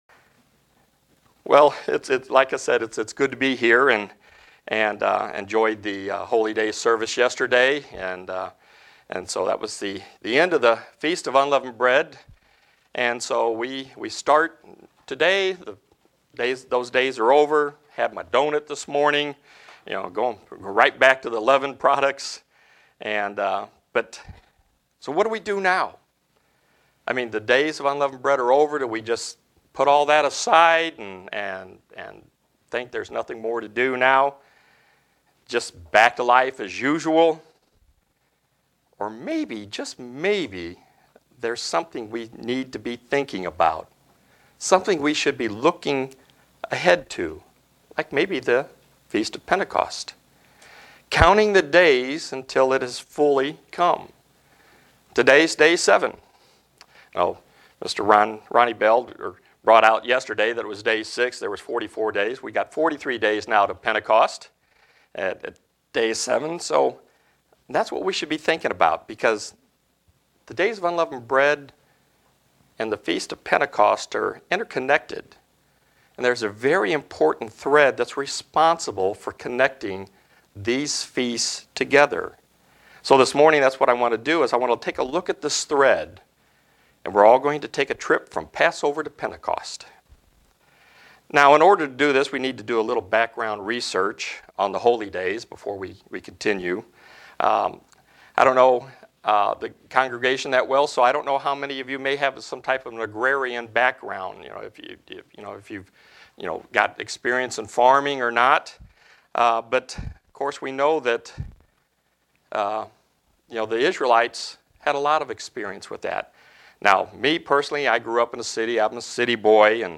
Given in Buffalo, NY
Print These days are connected and lead to our futur in Gods' Kingdom. sermon Studying the bible?